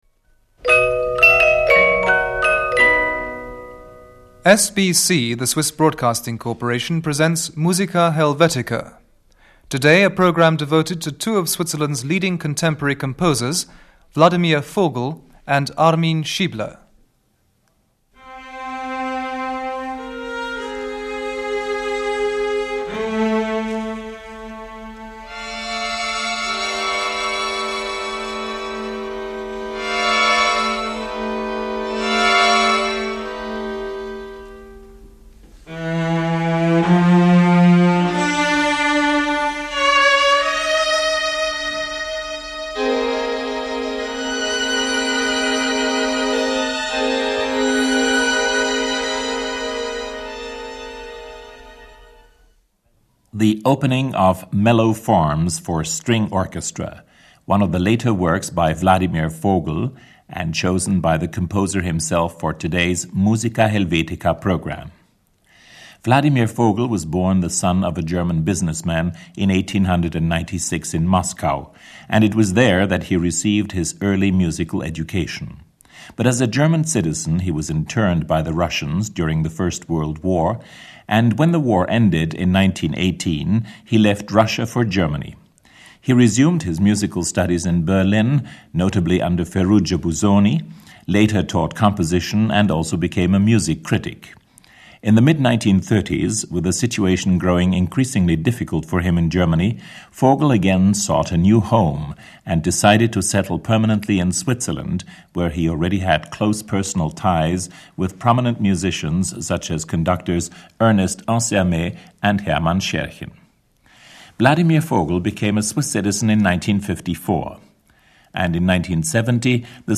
percussion.
piano.